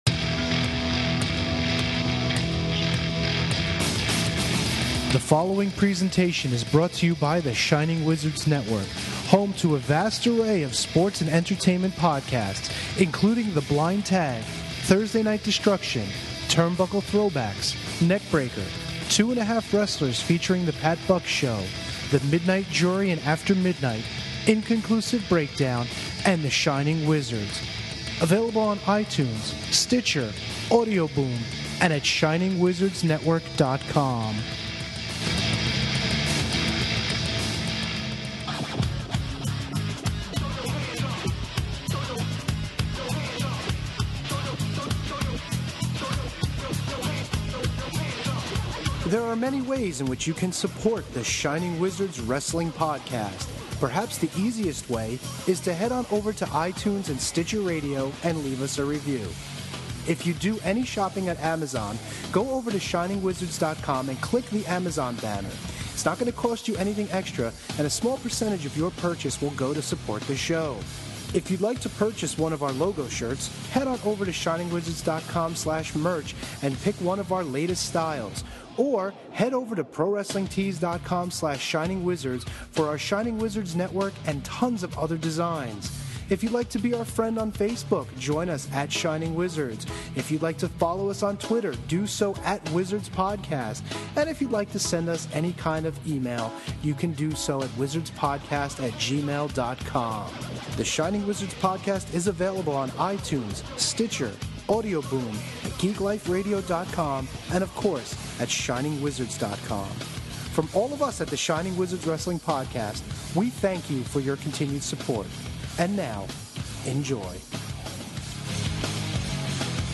Debate gets heated over Ryback and Bray Wyatt, and there’s plenty of stuttering and slurring to go around.